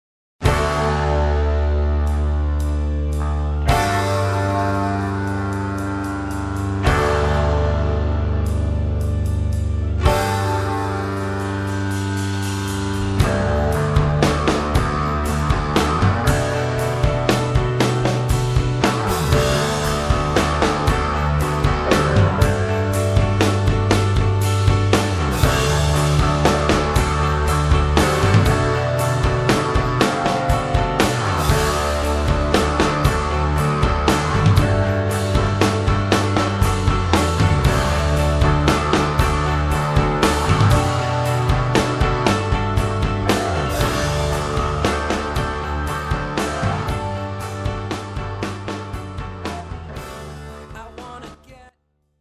Recorded at Pachyderm Studios, Cannon Falls, MN